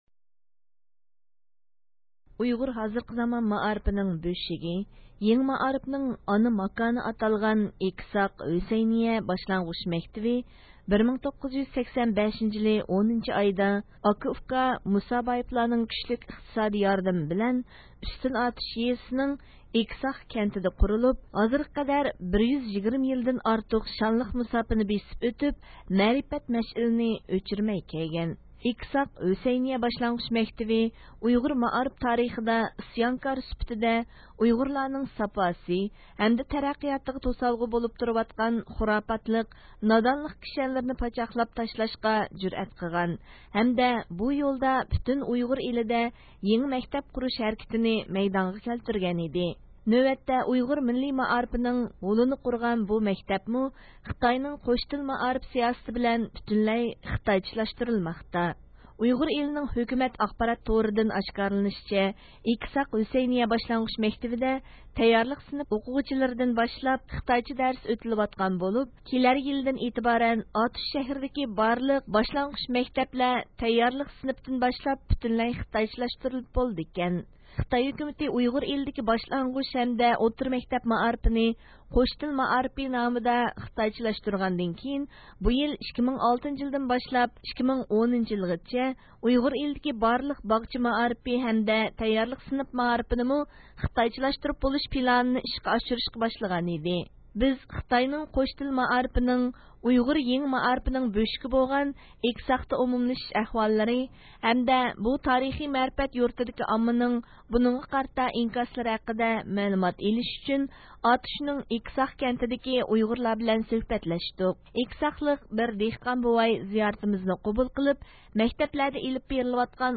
بىز خىتاينىڭ قوش تىل مائارىپىنىڭ، ئۇيغۇر يېڭى مائارىپىنىڭ بۆشۈكى بولغان ئېكىساقتا ئومۇملىشىش ئەھۋاللىرى ھەمدە بۇ تارىخىي مەرىپەت يۇرتىدىكى ئاممىنىڭ بۇنىڭغا قارىتا ئىنكاسلىرى ھەققىدە مەلۇمات ئېلىش ئۈچۈن ئاتۇشنىڭ ئېكىساق كەنتىدىكى ئۇيغۇرلار بىلەن سۆھبەتلەشتۇق.
ئېكىساقلىق بىر دېھقان بوۋاي زىيارىتىمىزنى قوبۇل قىلىپ، مەكتەپلەردە ئېلىپ بېرىلىۋاتقان قوش تىللىق مائارىپ توغرىسىدا ئىنتايىن ئېھتىياتچانلىق بىلەن جاۋاب بەردى.
بۇ ھەقتە زىيارىتىمىزنى قوبۇل قىلغان بىر ياش ئاتا، ئەۋلادلارنىڭ كەلگۈسىدە ئۆز ئانا تىلى ھەمدە مەدەنىيىتىنى ساقلاپ قالالىشىدىن ئەندىشە قىلىدىغانلىقىنى ئىپادىلىدى.